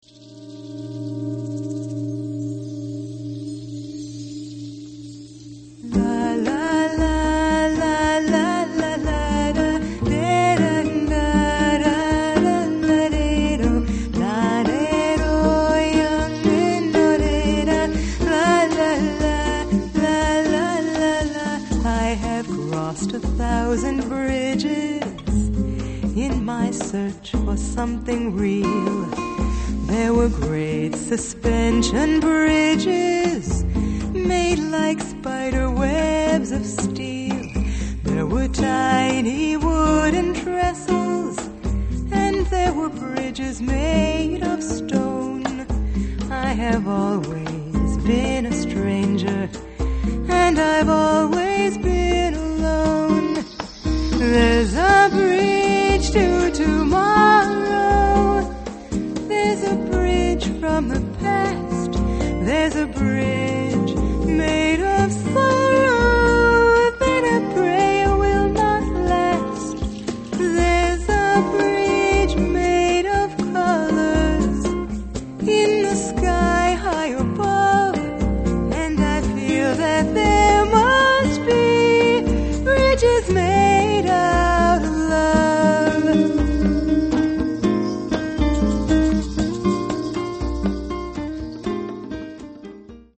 Jazz Vocalist
vocals
percussion (4, 11, 15)
Alvarez classical guitar (4, 11, 15)